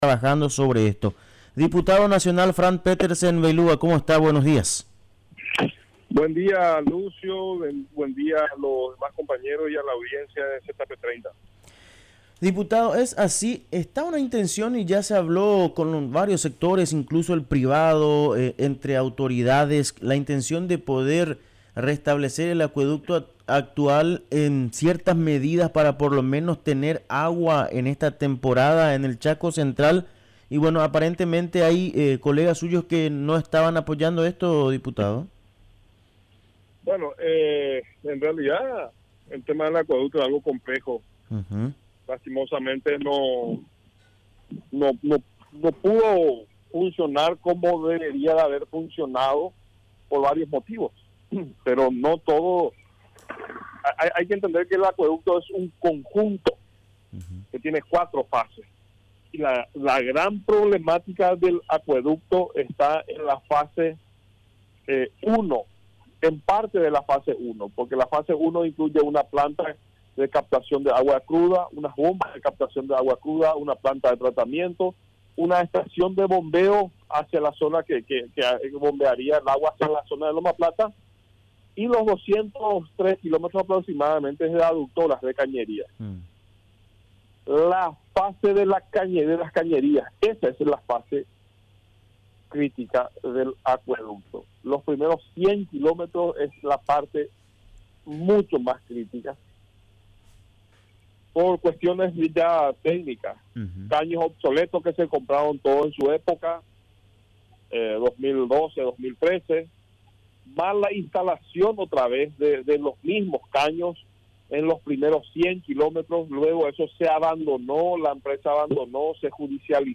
Respecto al tema del acueducto conversamos con el diputado Fran Petersen.